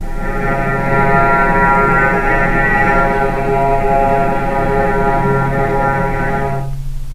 vc_sp-C#3-pp.AIF